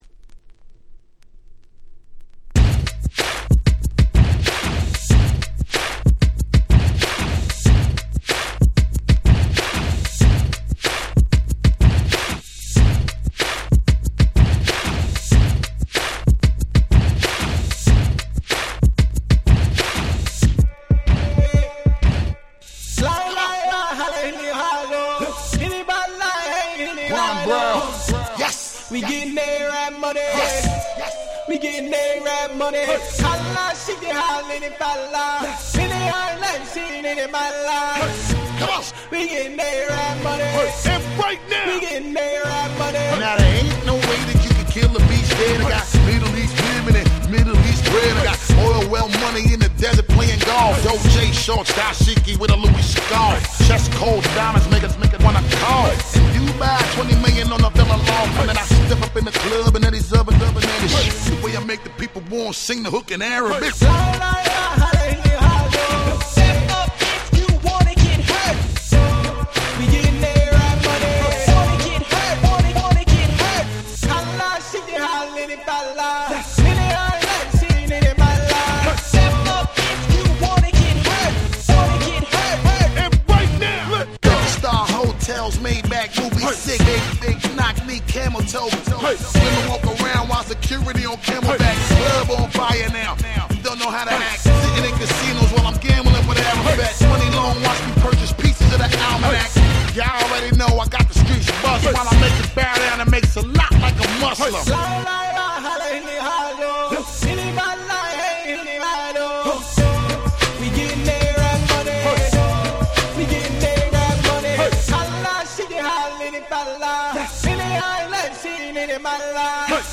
DJがPlay中にBPMを変えるのに超便利なトランジション物を全6曲収録しためちゃ使える1枚！！
(94BPM-122BPM)